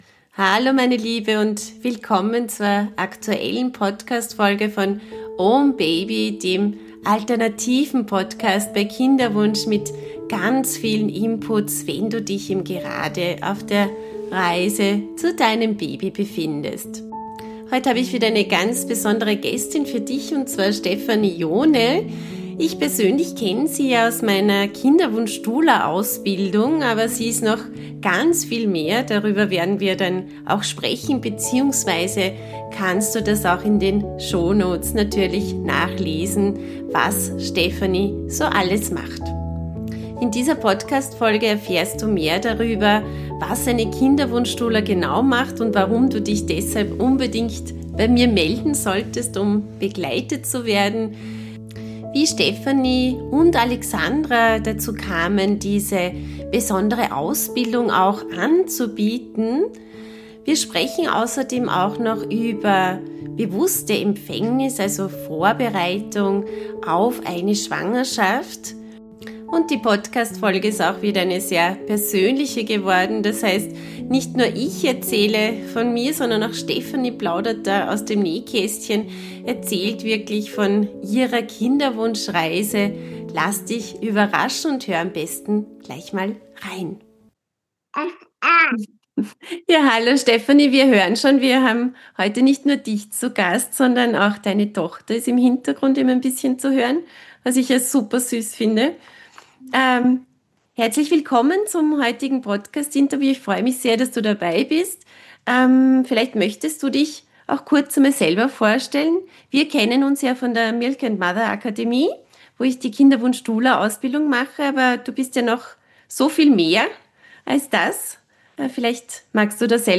Ein Gespräch voller Aha-Momente, Tiefe und Vertrauen – über die Verbindung zu sich selbst, das Loslassen von Kontrolle und den Glauben daran, dass Wunder möglich sind.